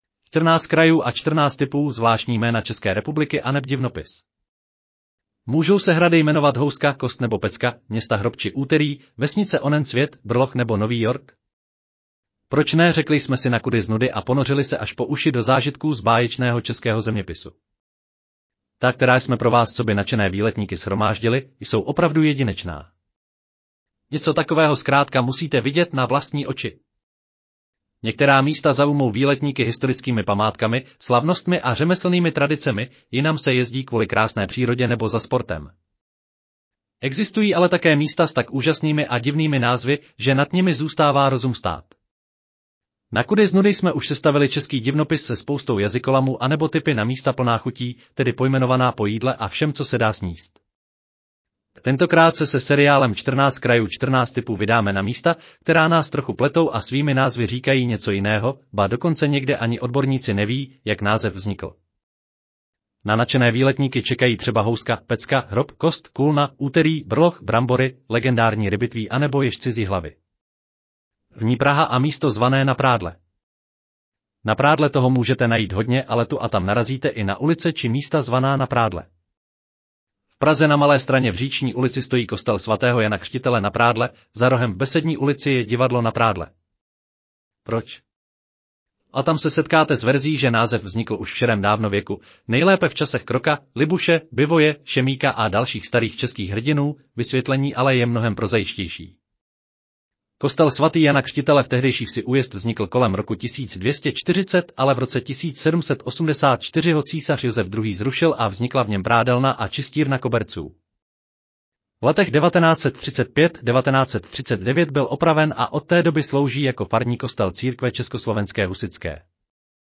Audio verze článku Čtrnáct krajů & čtrnáct tipů: zvláštní jména České republiky aneb divnopis